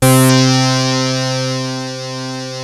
OSCAR C4 2.wav